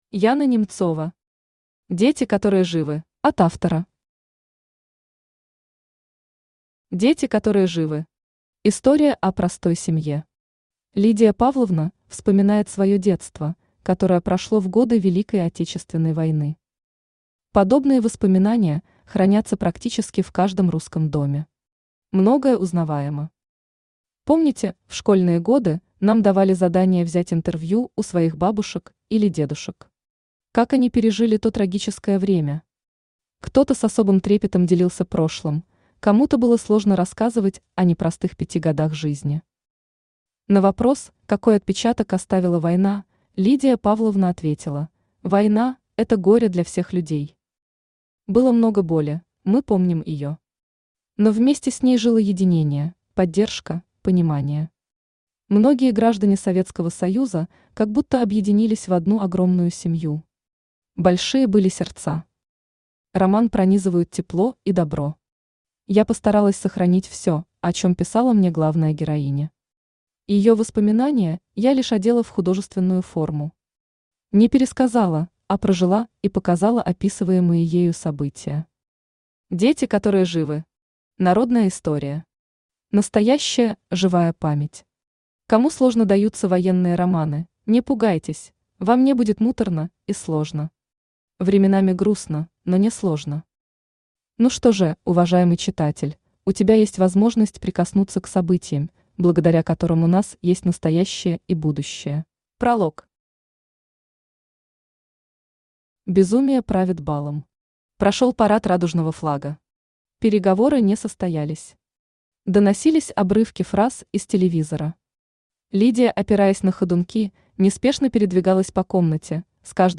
Читает: Авточтец ЛитРес